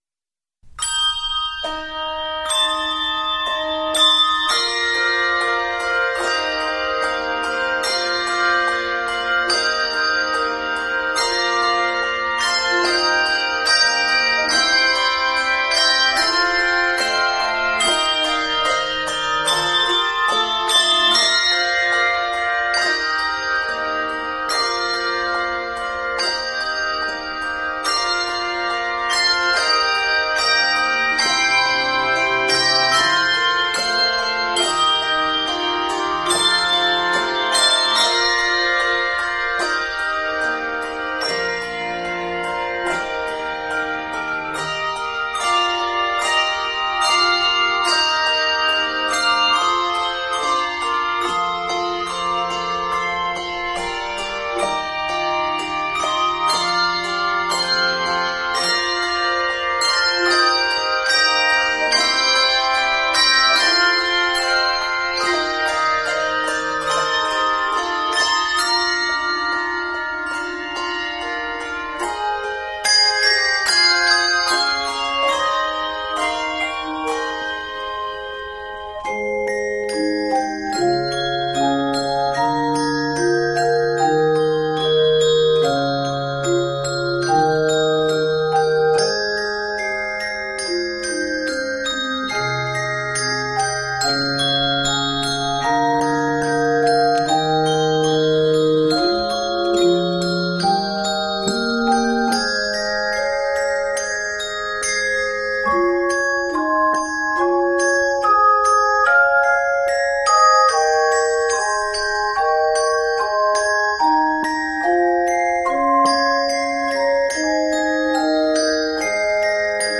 Scored in Bb, Ab, and G Majors, this piece is 59 measures.